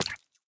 Minecraft Version Minecraft Version 1.21.5 Latest Release | Latest Snapshot 1.21.5 / assets / minecraft / sounds / mob / tadpole / death2.ogg Compare With Compare With Latest Release | Latest Snapshot
death2.ogg